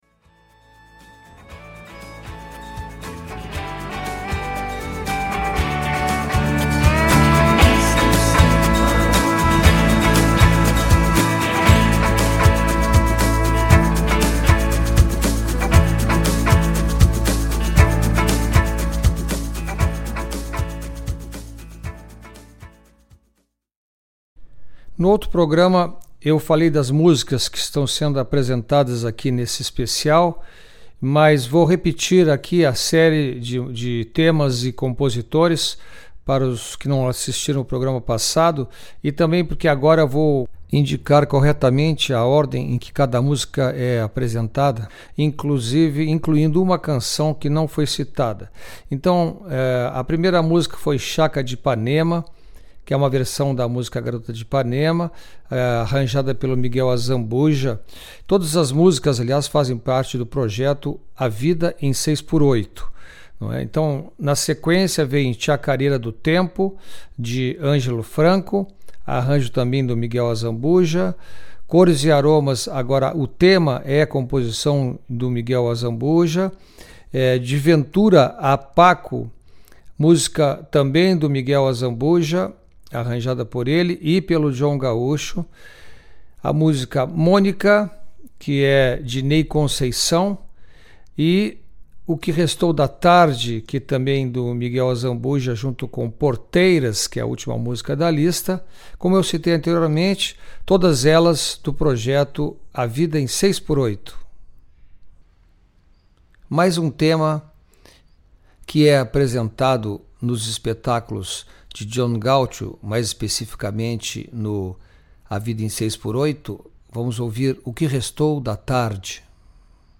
Conversa boa como uma roda de chimarrão.